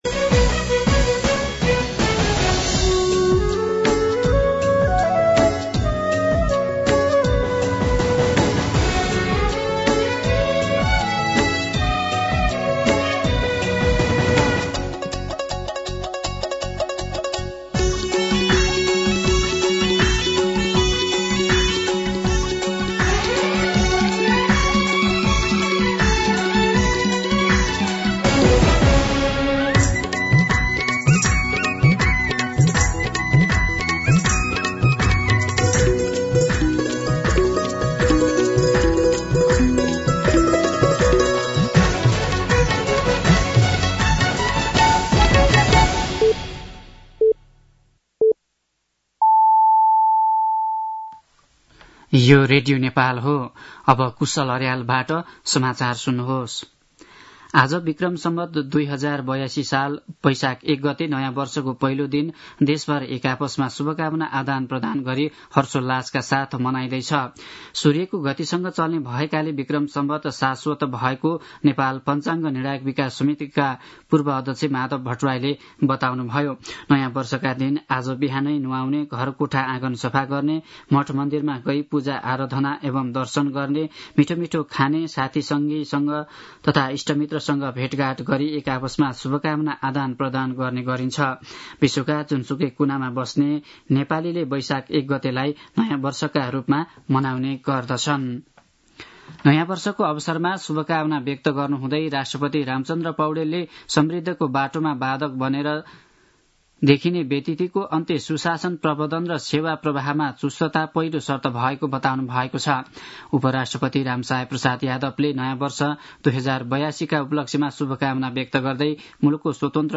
दिउँसो ४ बजेको नेपाली समाचार : १ वैशाख , २०८२
4pm-news.mp3